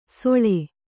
♪ soḷe